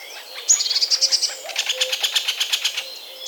Pic épeiche (chant){Ax} (ki)* saccadé perçant {
Registre Aigu
Phrase à x Syllabes en nombre multiple
Syllabe "ki"
Itération " ( )* " sur "ki"